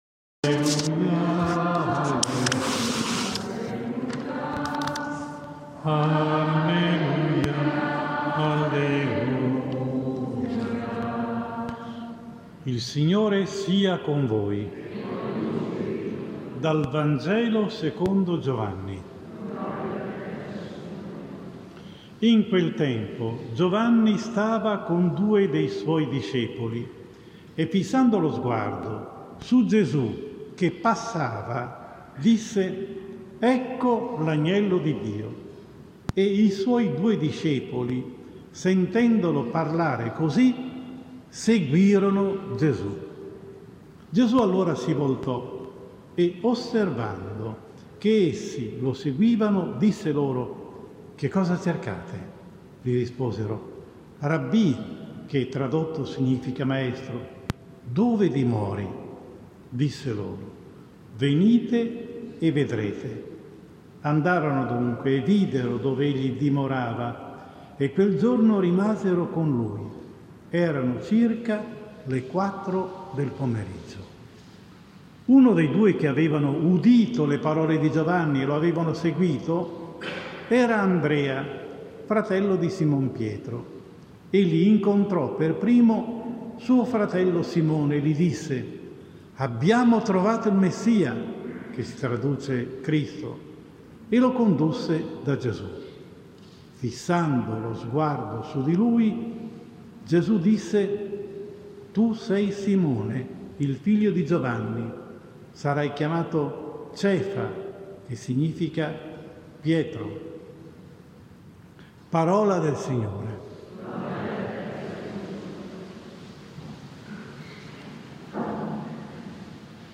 17 gennaio 2021 II DOMENICA del tempo ordinario Anno B – omelia